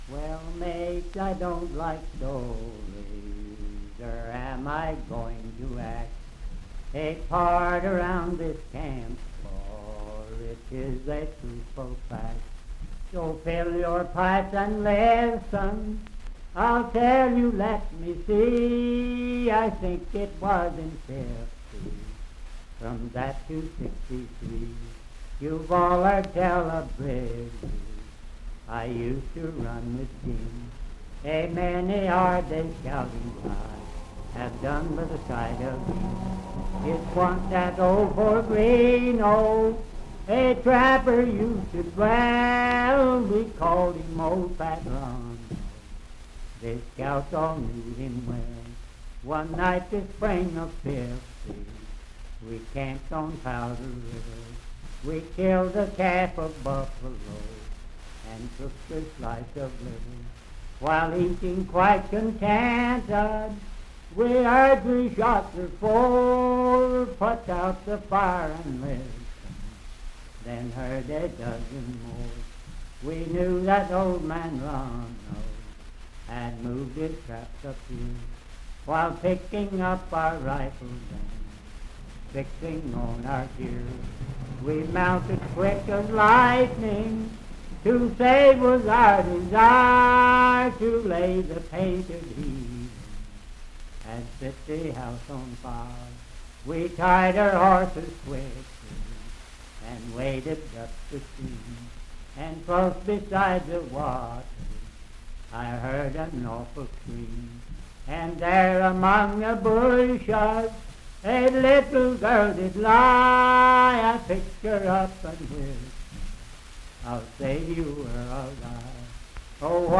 Unaccompanied vocal music and folktales
Verse-refrain 22(8).
Voice (sung)